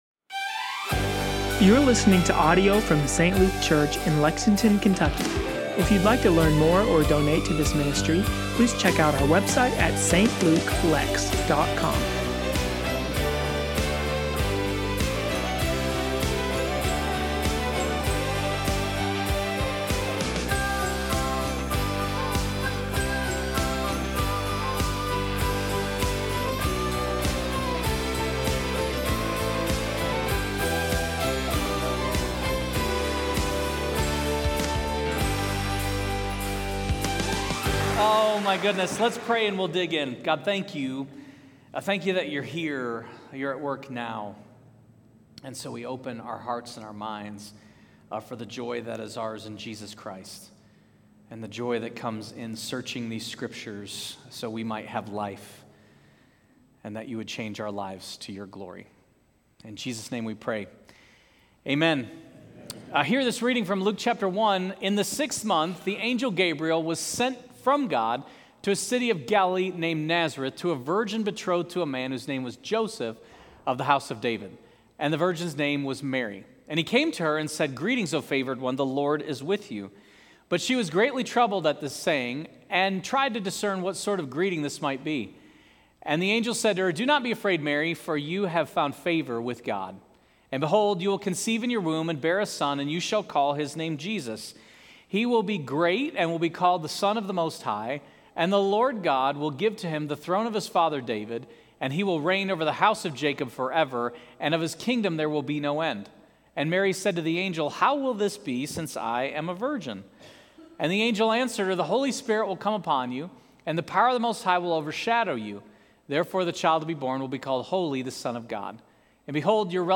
St. Luke Church Lexington – Sermons & Teachings